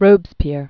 (rōbzpîr, -pē-âr, rô-bĕs-pyĕr), Maximilien François Marie Isidore de 1758-1794.